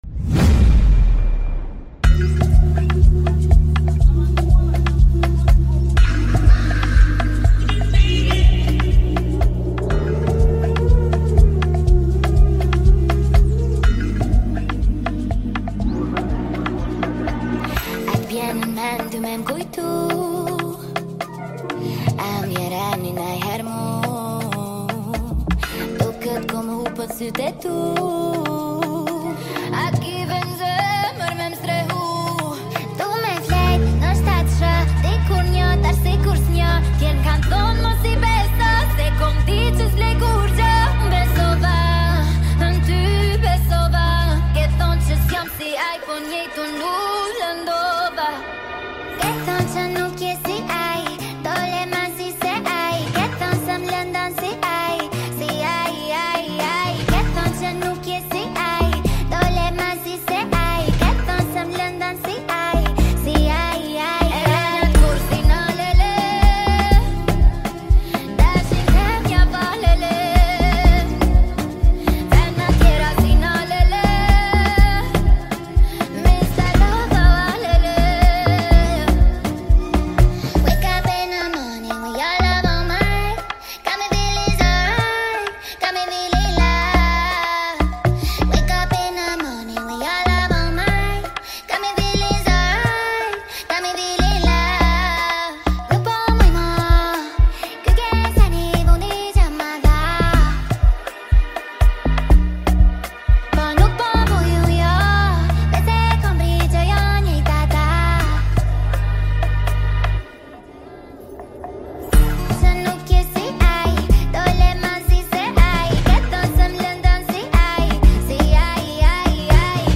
Спидап из тик тока